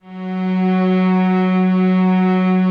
Index of /90_sSampleCDs/Optical Media International - Sonic Images Library/SI1_Swell String/SI1_Octaves